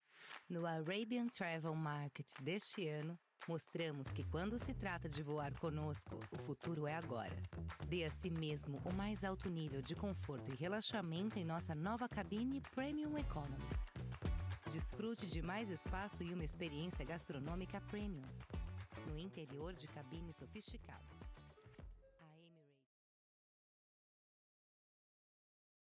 With a versatile, mature, and pleasant voice, I am able to adapt my narration style to the needs of the project, creating a unique and engaging experience for the listener.
I have a professional Home Studio equipped with Neumann 107, Mac, SSL2 Interface, AKG Headphones, soundproofed room and source connect for remote recordings.